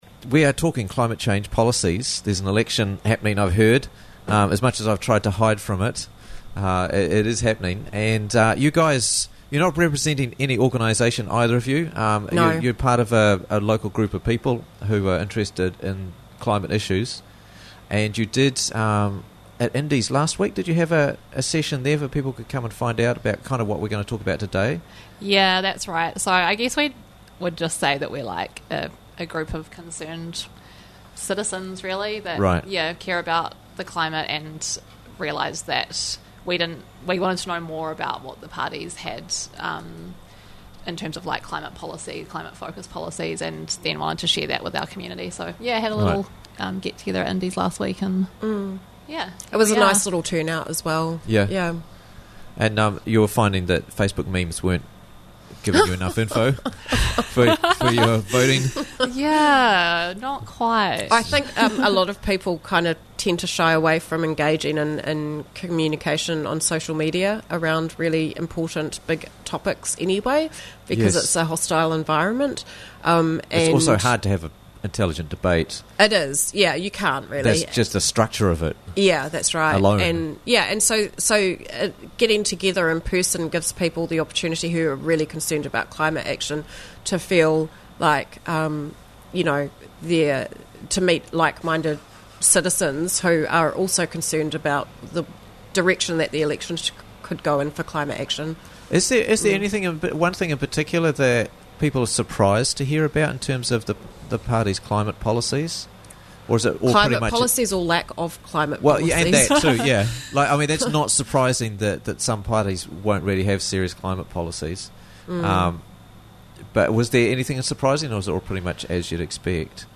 Vote for Climate - Interviews from the Raglan Morning Show